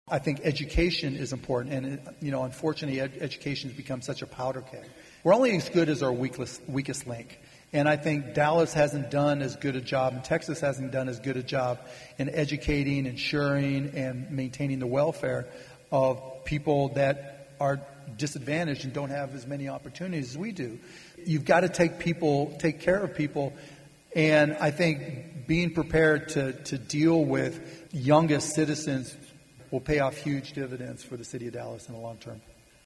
Hear more of Cuban's comments on the future of Dallas during his talk at the Dallas Regional Chamber's annual meeting in January.